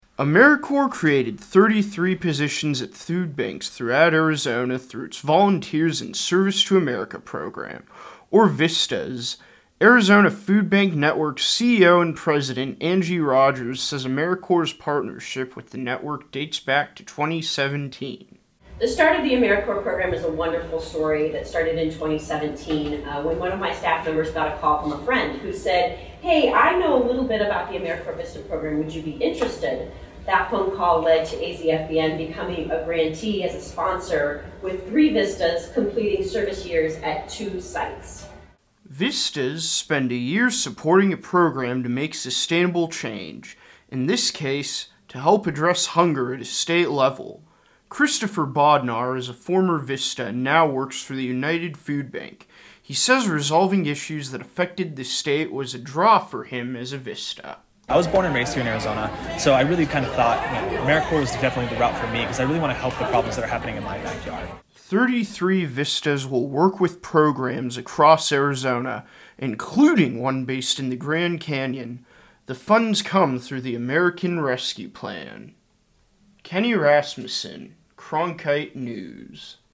HOST INTRO: